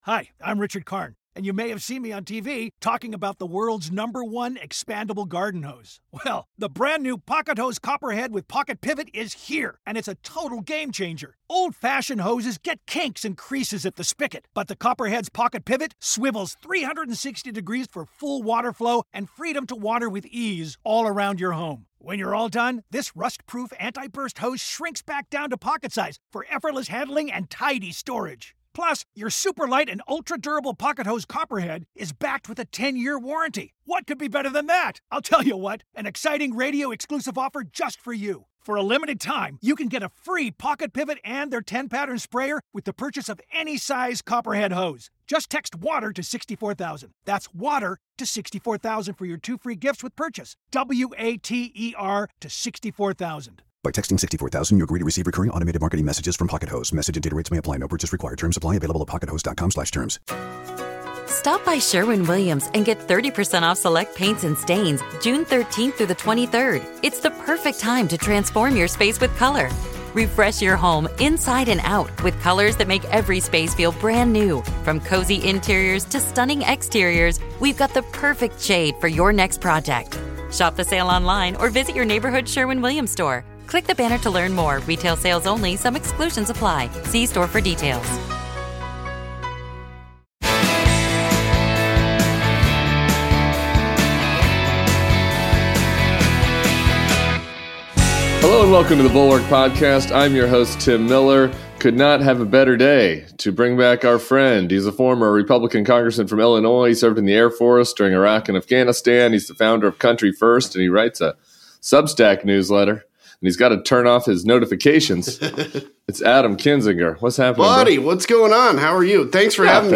Adam Kinzinger joins Tim Miller for the weekend pod.